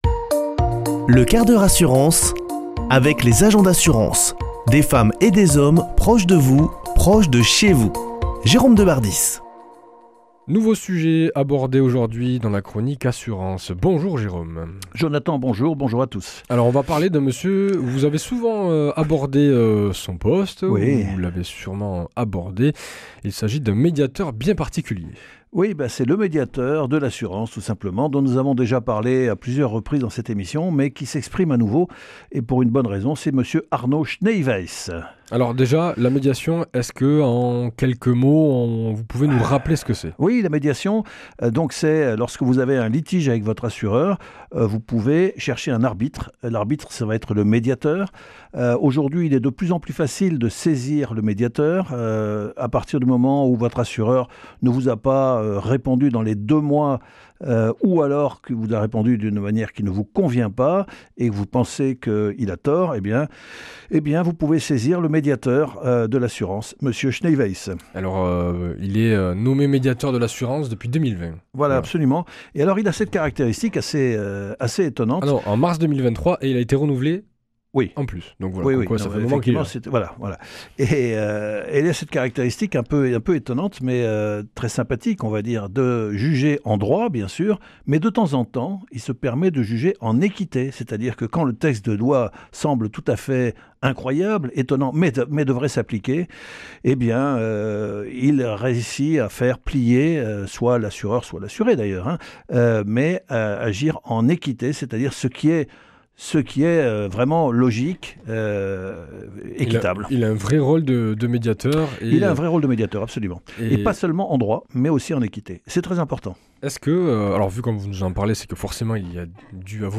mardi 25 février 2025 Chronique le 1/4 h assurance Durée 5 min
Une émission présentée par